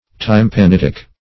\Tym`pa*nit"ic\